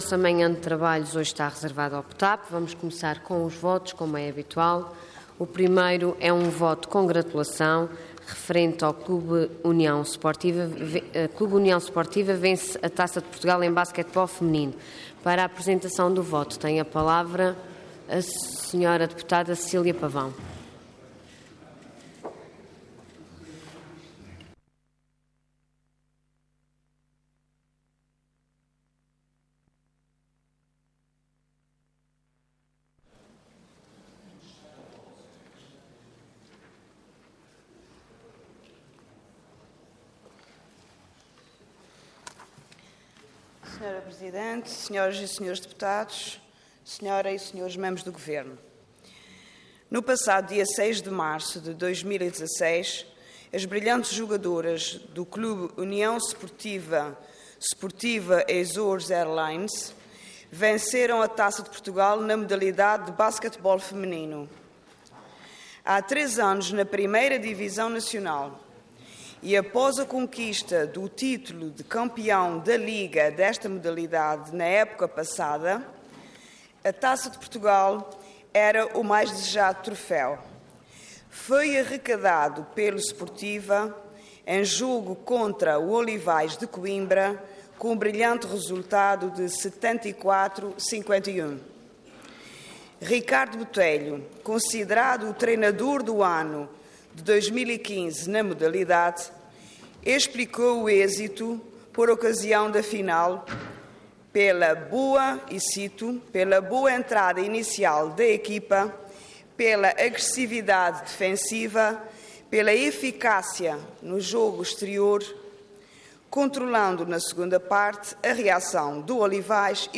Detalhe de vídeo 16 de março de 2016 Download áudio Download vídeo Processo X Legislatura Clube União Sportiva vence Taça de Portugal em Basquetebol Feminino Intervenção Voto de Congratulação Orador Cecília Pavão Cargo Deputada Entidade PS